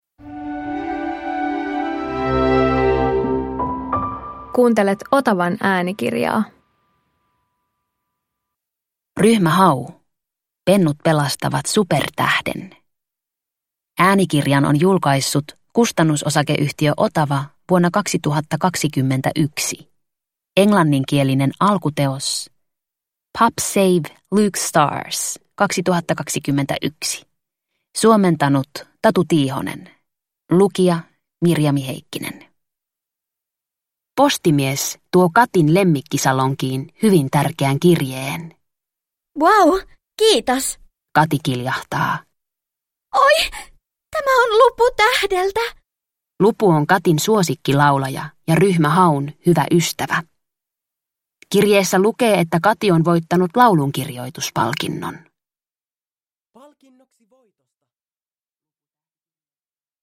Ryhmä Hau - Pennut pelastavat supertähden – Ljudbok – Laddas ner